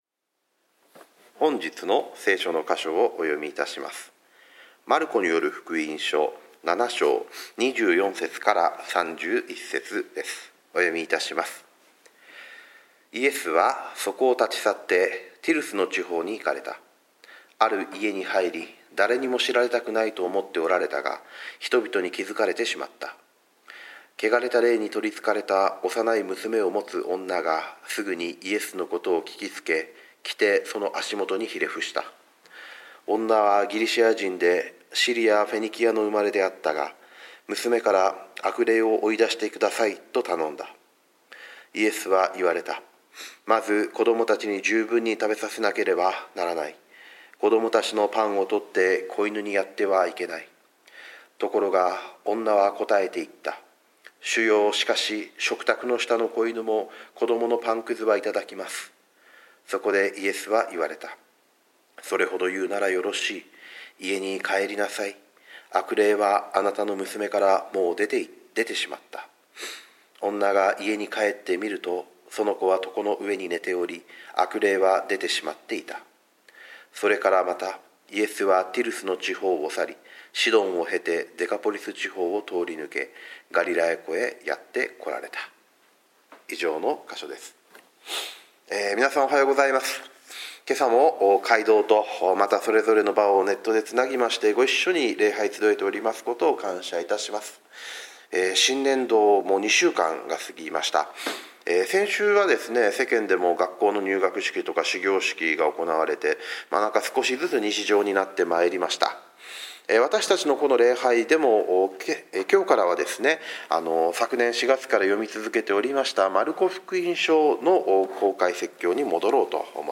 主日礼拝